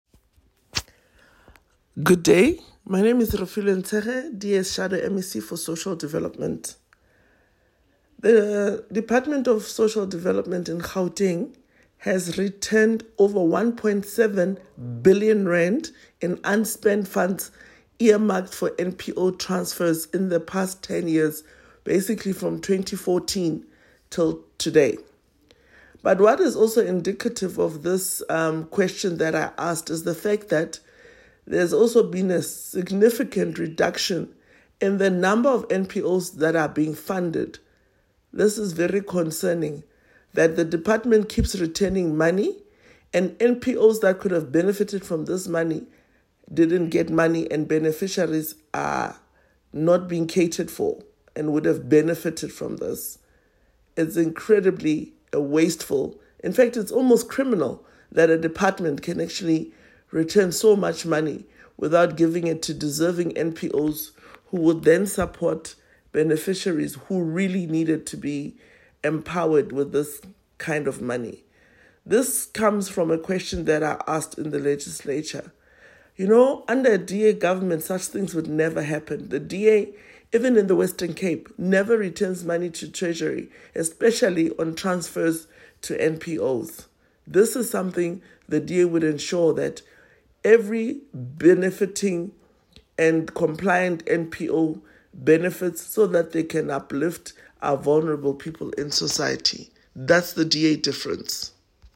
English and Sesotho soundbites by Refiloe Nt’sekhe MPL.